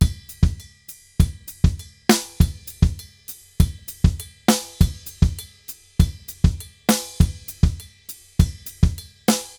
Drums_Baion 100_3.wav